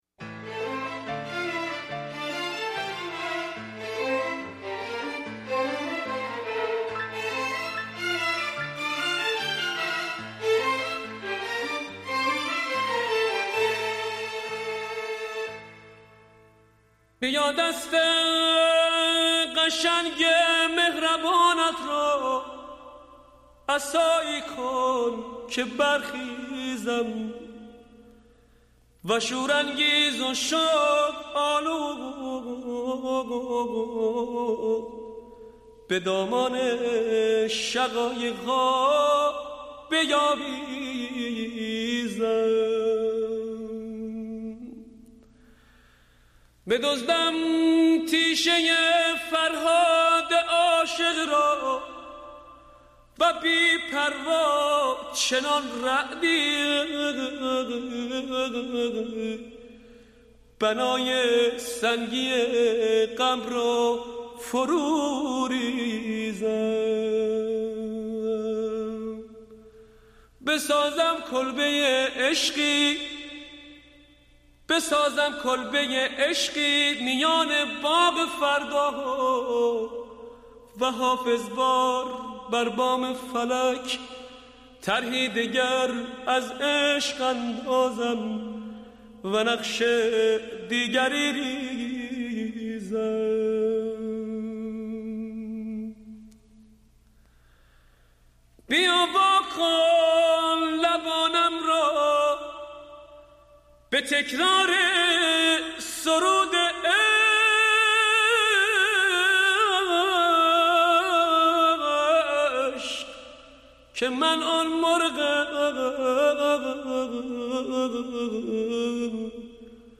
آهنگ شنیدنی و پر احساس
نوای دلنشین